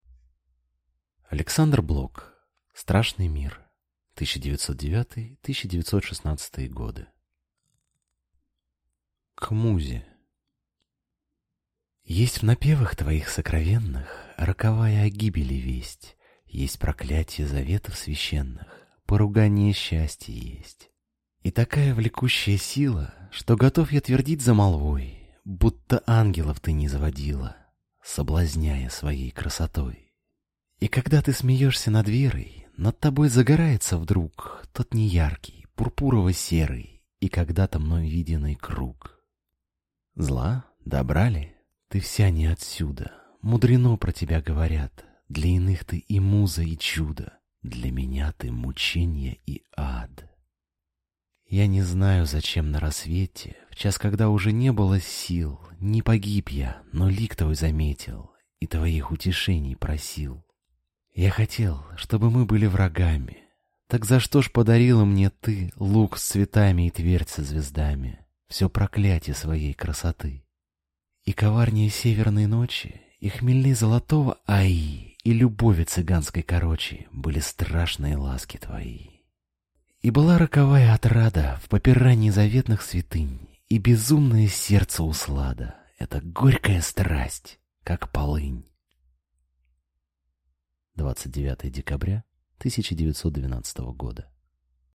Аудиокнига Страшный мир | Библиотека аудиокниг